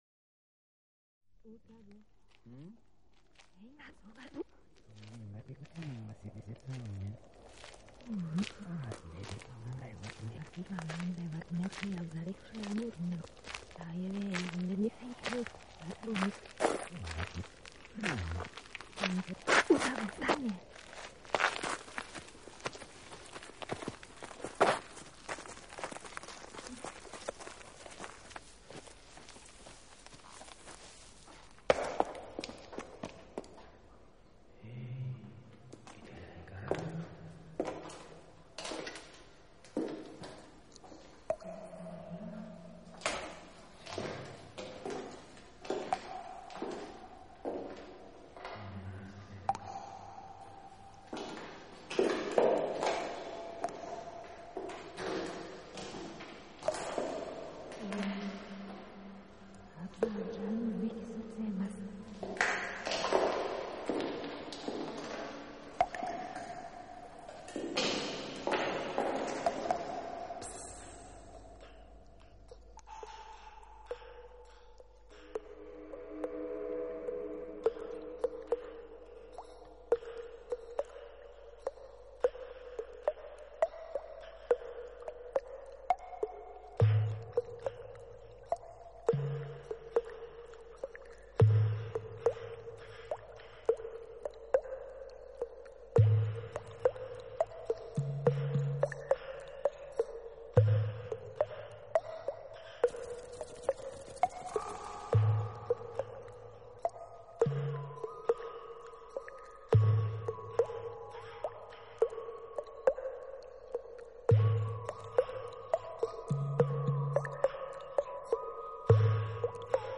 楚的辨别其中的乐器成分，乐器给人非常真实的临场感。